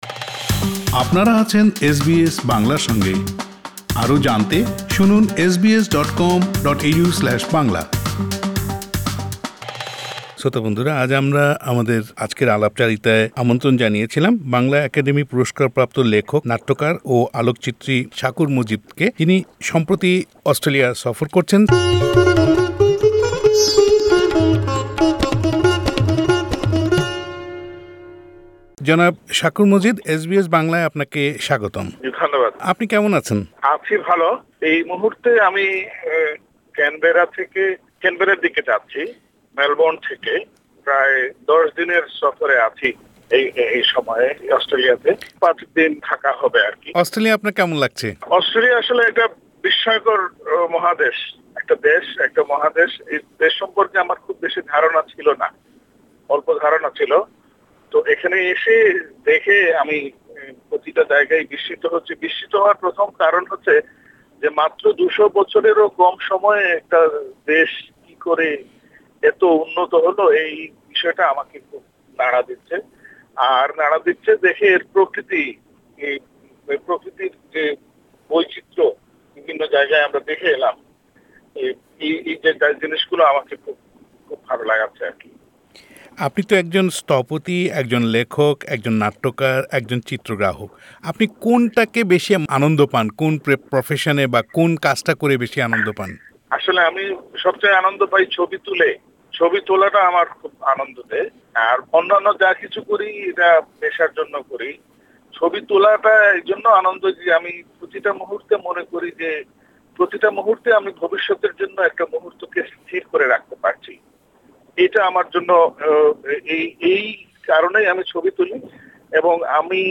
সম্প্রতি অস্ট্রেলিয়া সফর করছেন এসবিএস বাংলা মুখোমুখি হয়েছে এই গুণী মানুষটির। কথা বলেছি নানা বিষয় নিয়ে তার জীবনী ,কথা সাহিত্যিক হুমায়ুন আহমেদ এর সান্নিধ্য ইত্যাদি নানা বিষয়ে।